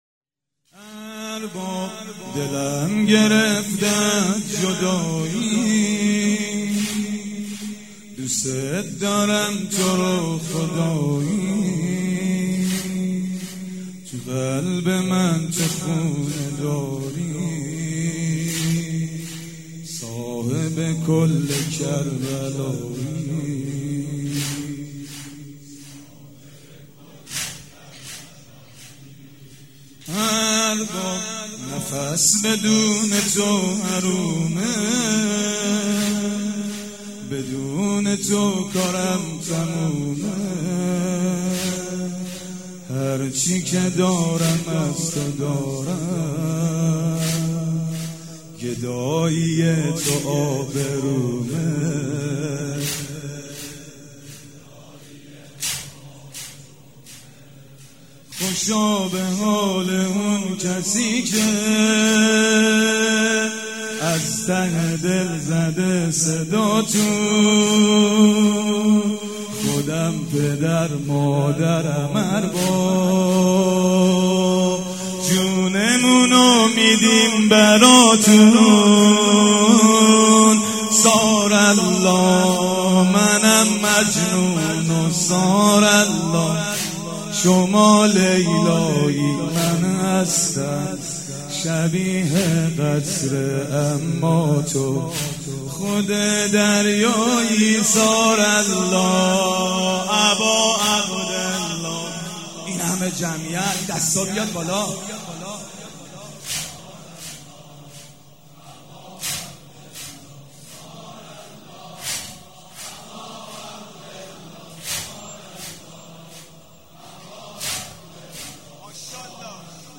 شب هفتم رمضان95، حاج محمدرضا طاهری
زمینه، روضه، مناجات